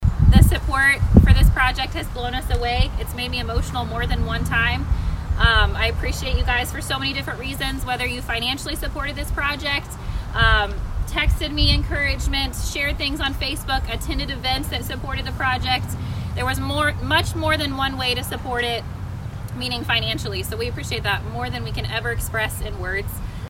Groundbreaking ceremony held for the Atlantic SplashPad Project